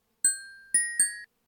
Hovering_parrot.ogg